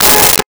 Car Horn 01
Car Horn 01.wav